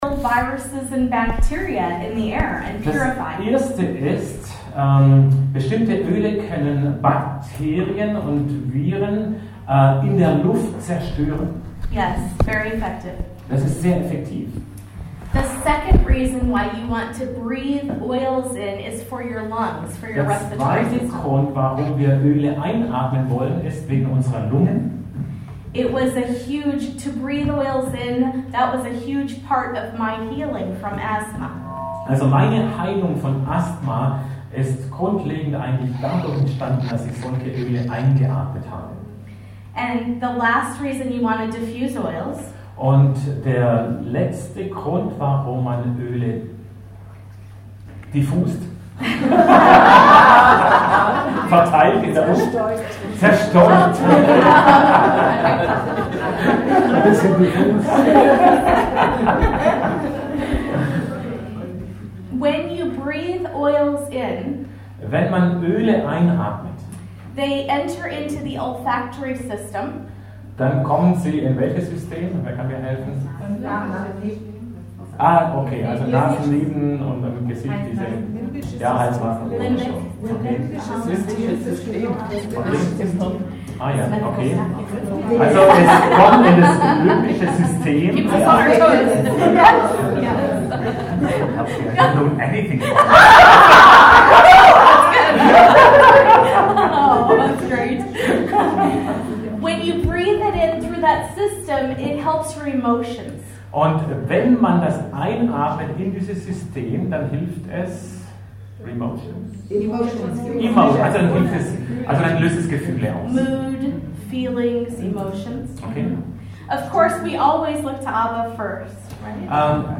Vom 02. und 03. Februar 2018 in der Gemeine Treffpunkt Leben Biberach, Riß.